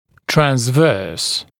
[trænz’vɜːs][трэнз’вё:с]транзверзальный, поперечный